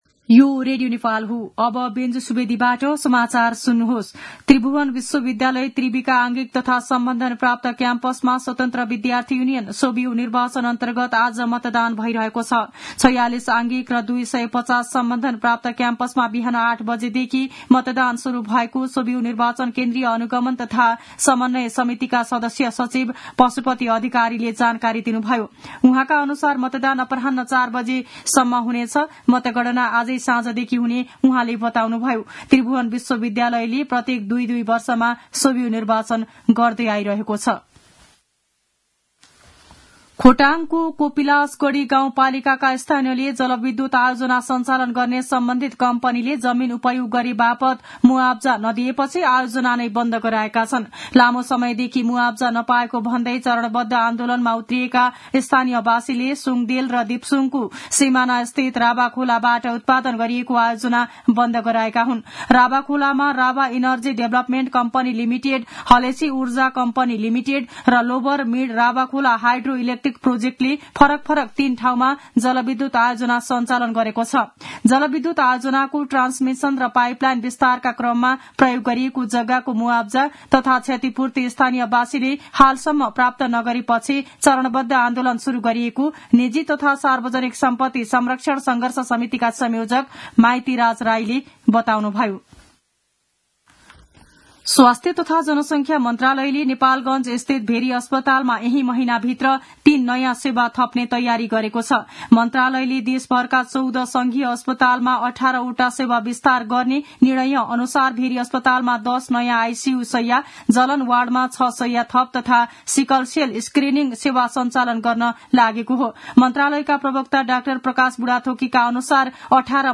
दिउँसो १ बजेको नेपाली समाचार : ५ चैत , २०८१